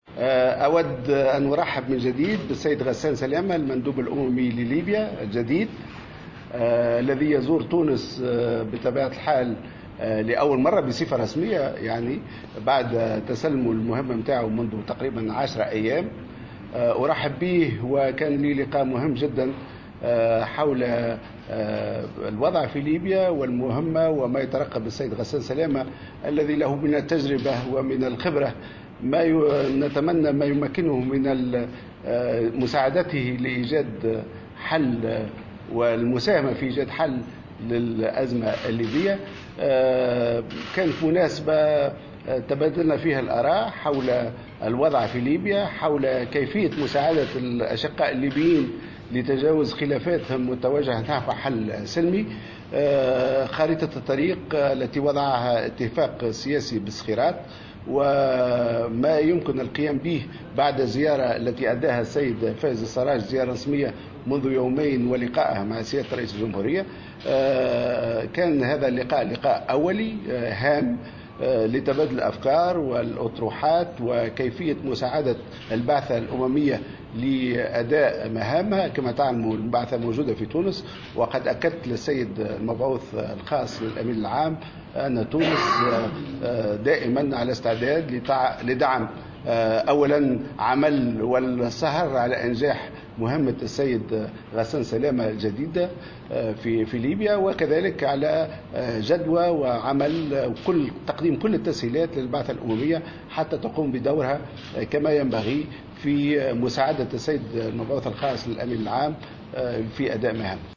و في علاقة بموقف الأمم المتحدة من مبادرة فائز السراج بخصوص تنظيم انتخابات في مارس المقبل في ليبيا أوضح غسان سلامة في تصريح لمراسلة الجوهرة اف ام، أن الأمم المتحدة لا يمكن أن تمنع الشعوب من إجراء انتخابات، مشددا على أن دورها يقتصر على التأطير والتأكد من شروط إجراء هذه الانتخابات.